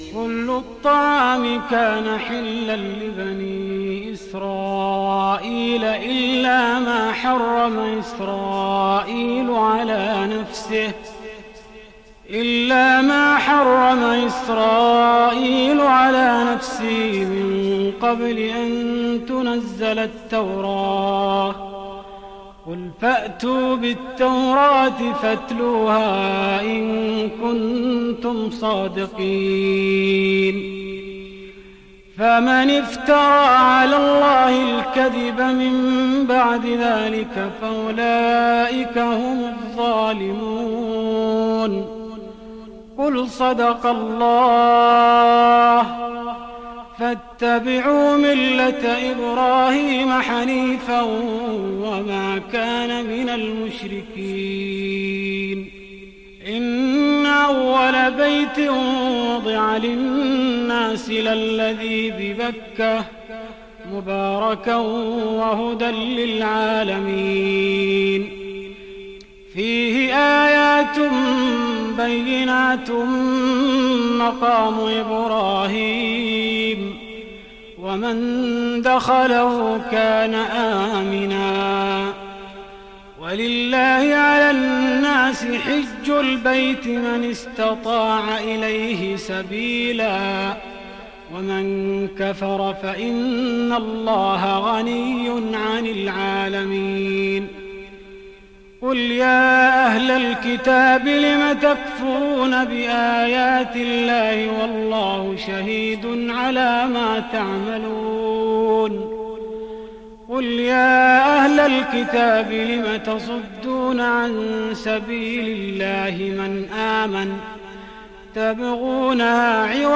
تهجد رمضان 1415هـ من سورة آل عمران (93-168) Tahajjud Ramadan 1415H from Surah Aal-i-Imraan > تراويح الحرم النبوي عام 1415 🕌 > التراويح - تلاوات الحرمين